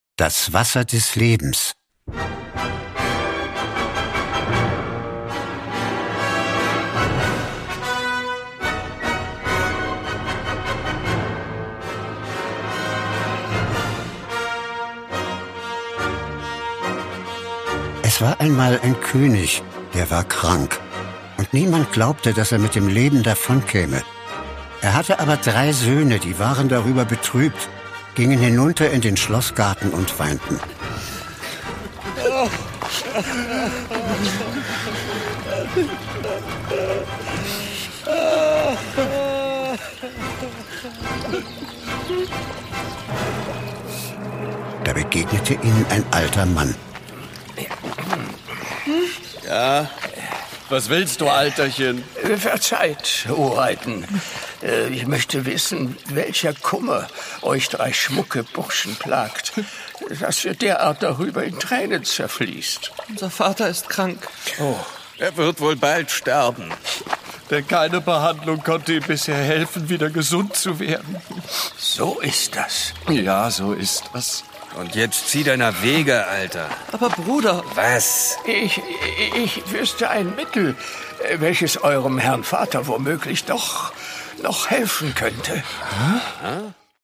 Produkttyp: Hörspiel-Download
Die berühmten Märchen-Klassiker der Brüder Grimm als aufwendige Hörspiel-Neuvertonungen für die ganze Familie.